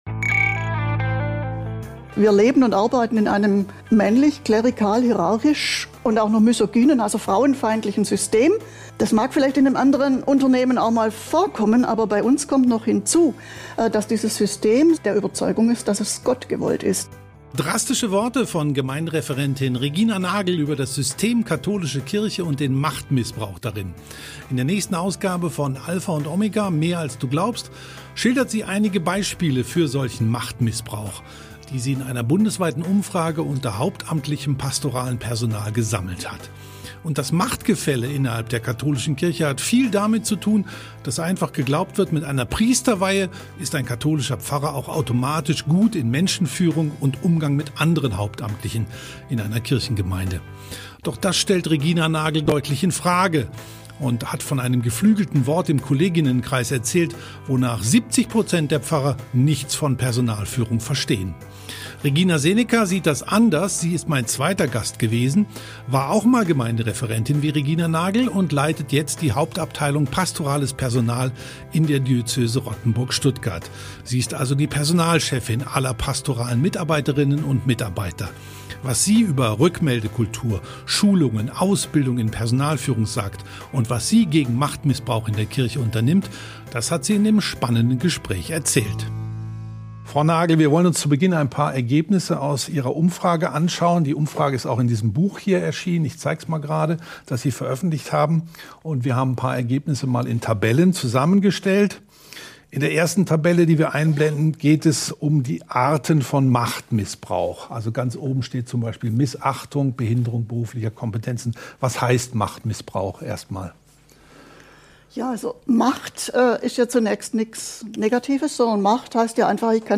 #135 Eine Gemeindereferentin und eine Personalchefin diskutieren über Machtmissbrauch ~ Alpha & Omega: Mehr als du glaubst Podcast